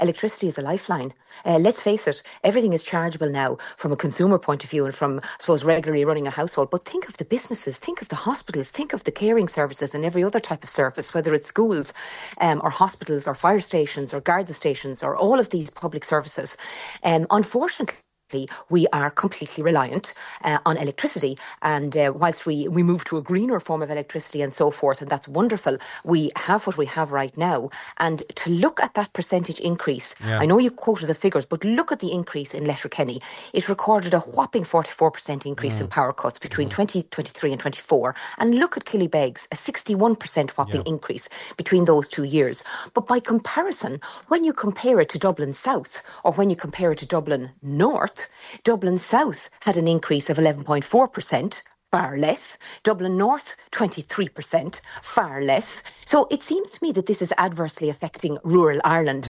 On today’s Nine til Noon Show, she acknowledged that weather can increase the risk of power outages in areas like Donegal, but says the disparity between the county and Dublin is stark…………….